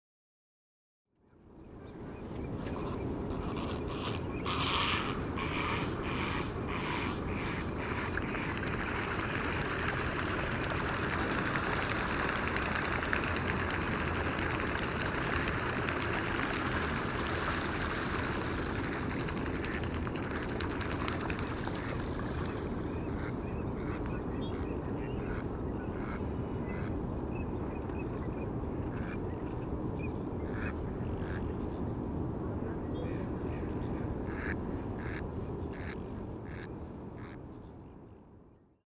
Me and my SPBA in a fifty-dollar canoe out on Smith and Bybee Lakes in Portland Oregon.
The parabolic dish is a Telinga, and the microphones are Shure WL-183.
This preserves an accurate stereo field.
Play the clip below, or click here, to hear the SPBA working on its maiden voyage.
This recording of a Great Blue Heron, ducks, and other wetland creatures
was edited with a parametric equalizer to tame a stifling urban roar.